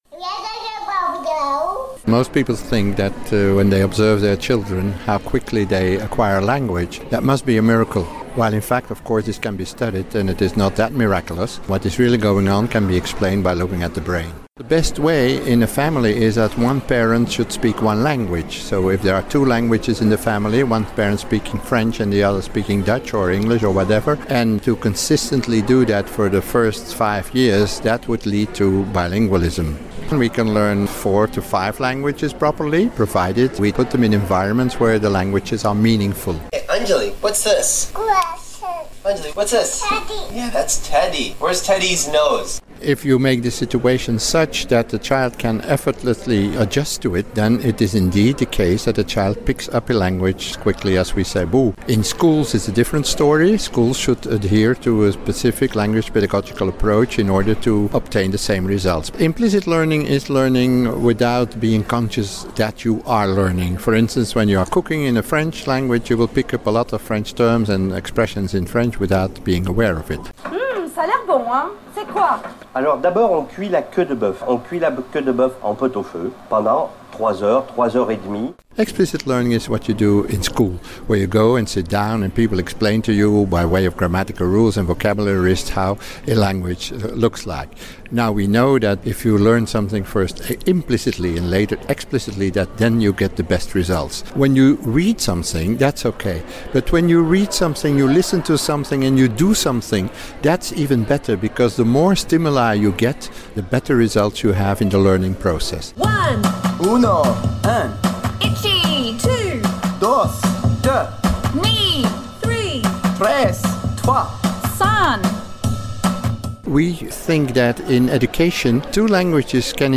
But what's the best way of teaching a child more than one language at once? We went to a talk at the ISF International School in W...